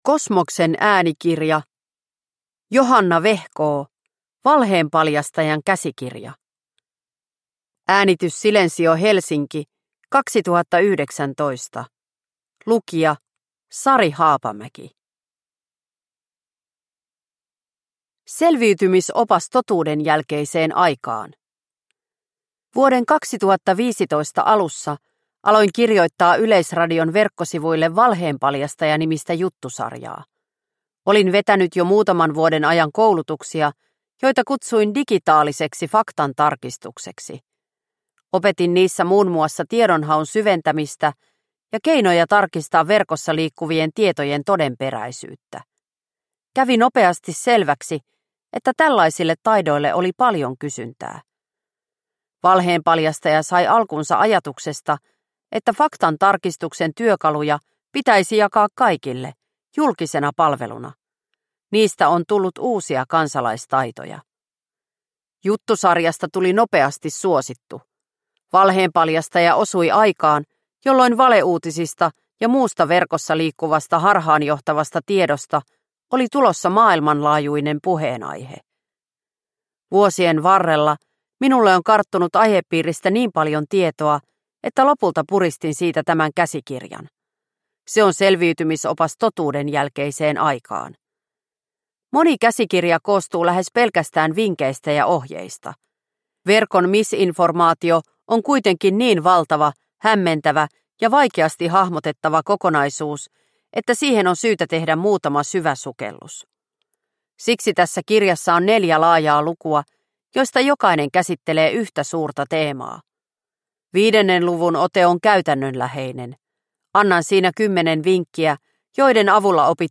Valheenpaljastajan käsikirja – Ljudbok – Laddas ner